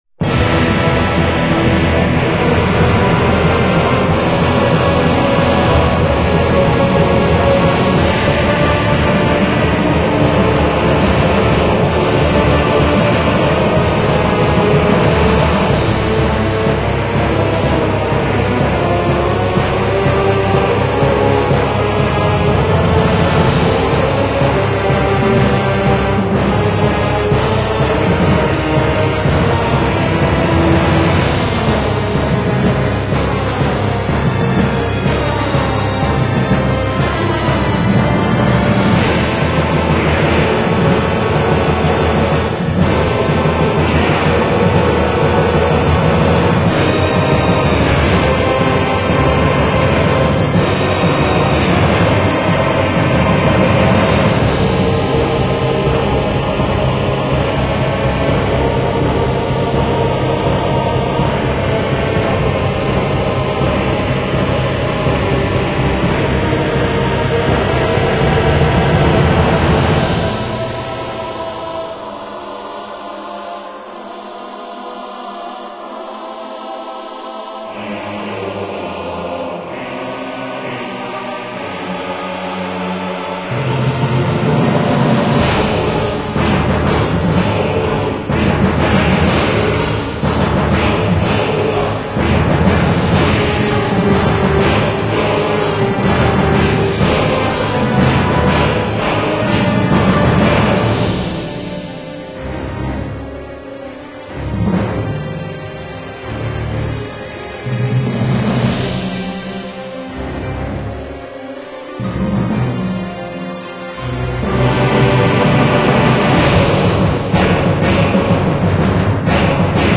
Жанр: Score
Исполнение исключительно инструментальное.